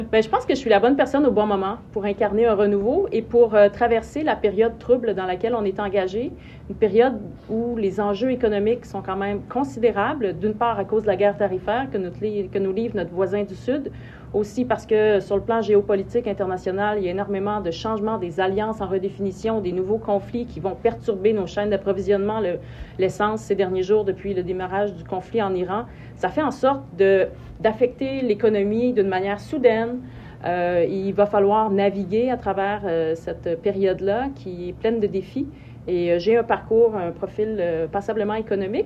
De passage dans nos studios dans l’émission du Retour, madame Fréchette répondait à la question : Pourquoi voter pour elle ?